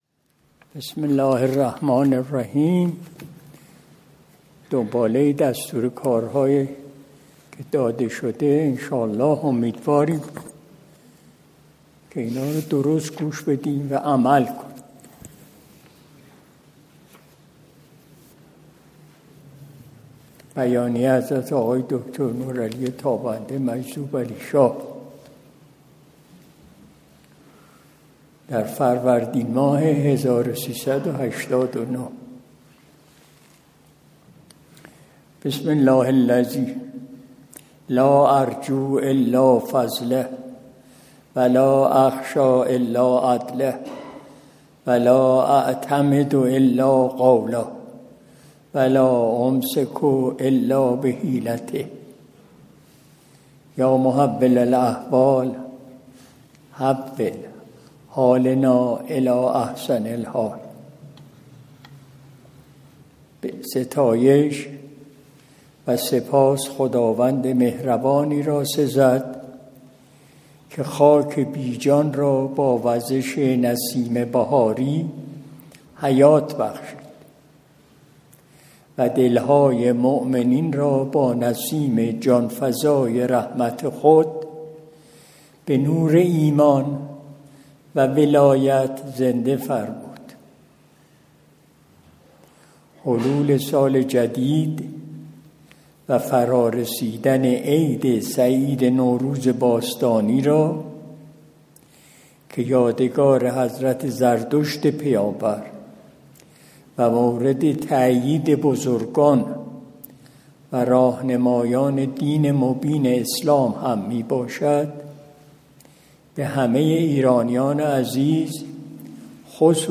قرائت بیانیۀ
مجلس شب دوشنبه ۲۰ آذر ۱۴۰۱ شمسی